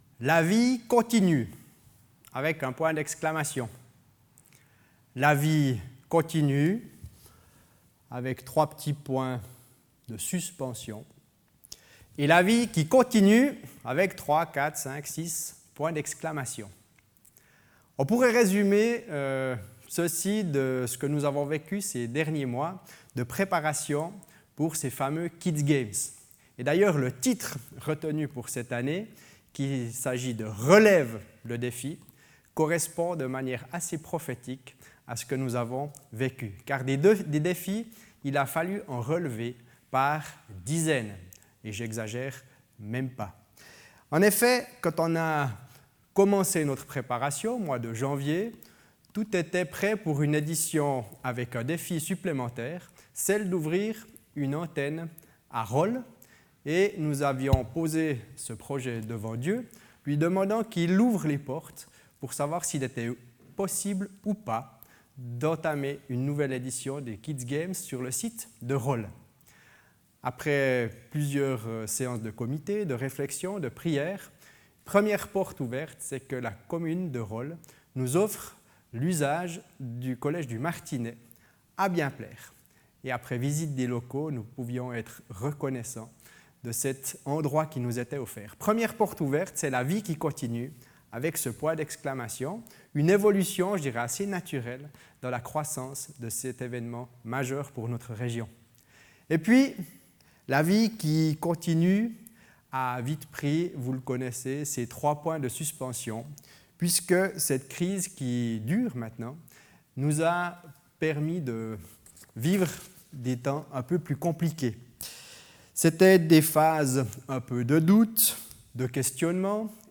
Culte du 9 août 2020 « la vie continue »